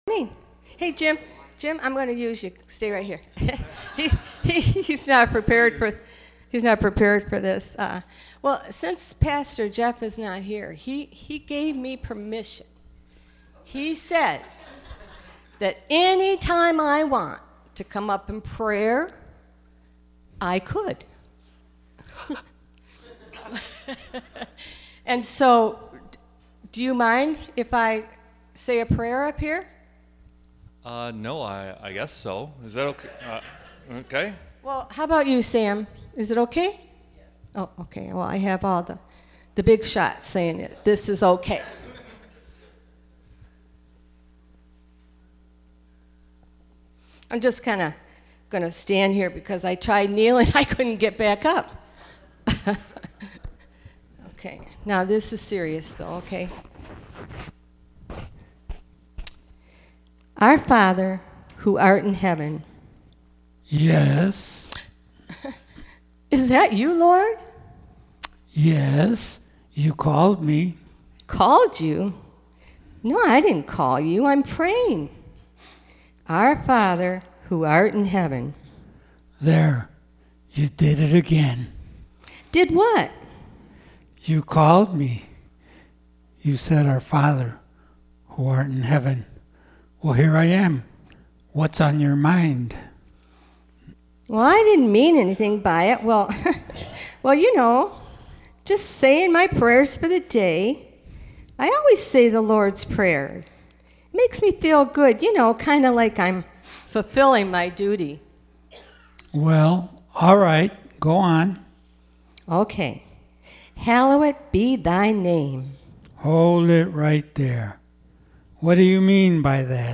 2 Peter 3:13 Service Type: Sunday Morning %todo_render% « New Year’s Goal